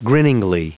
Prononciation du mot : grinningly
grinningly.wav